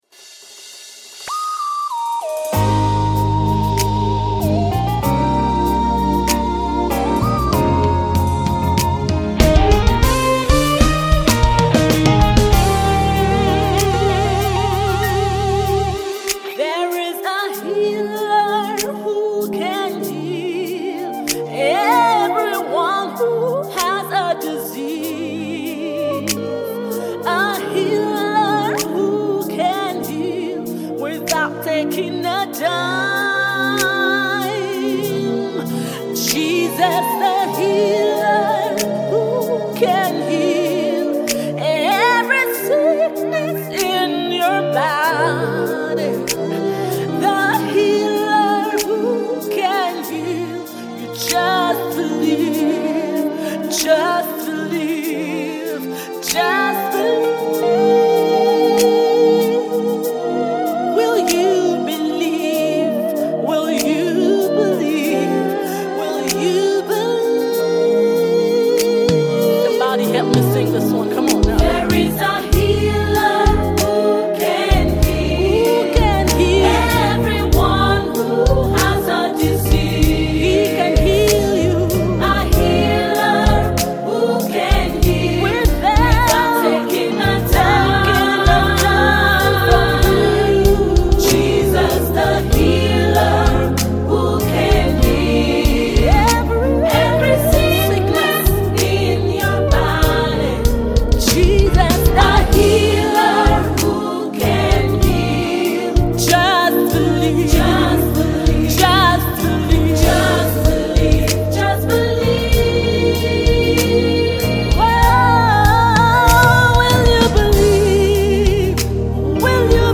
Nigerian gospel musician